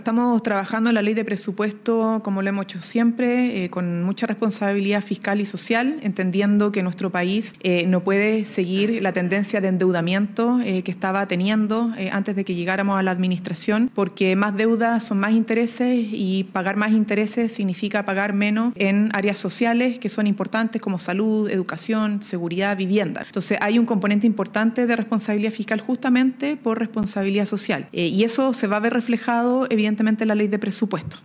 En su tradicional punto de prensa de los lunes, la ministra secretaria general de Gobierno, Camila Vallejo, adelantó los principales lineamientos de la Ley de Presupuestos 2026, que el Ejecutivo ingresará próximamente al Congreso.